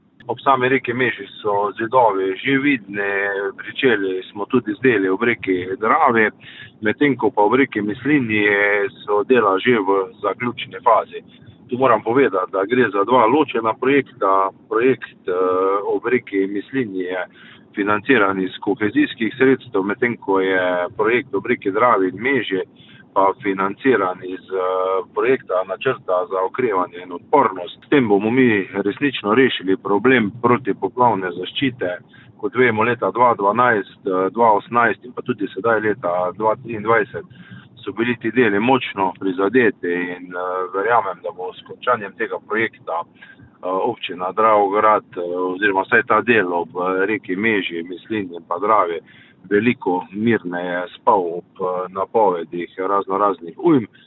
Zaščitni zidovi v kombinaciji z vgradnimi paneli in protipoplavni nasipi naj bi zagotovili, da se katastrofalne posledice poplav ne ponovijo več. Dravograjski župan Anton Preksavec: